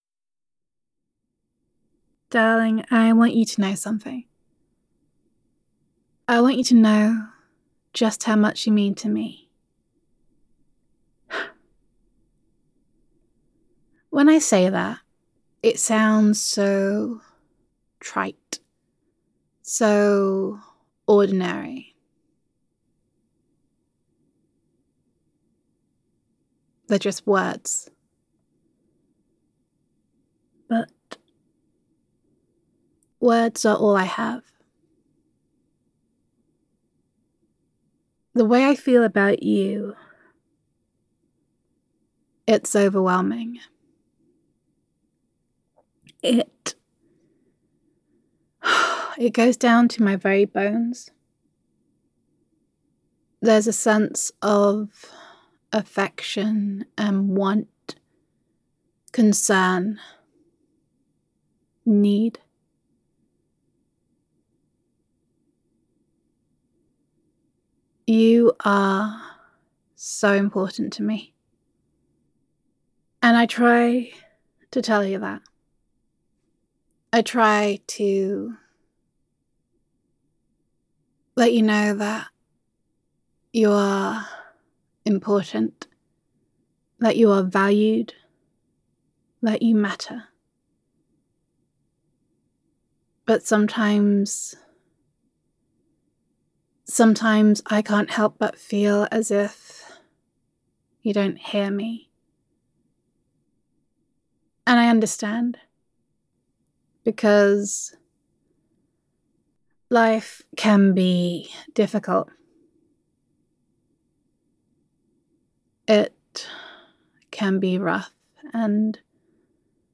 [F4A] You Are Important to Me [Appreciation][Reassurance][Recognition][I Believe in You][Adoration][Gender Neutral][Loving Girlfriend Roleplay]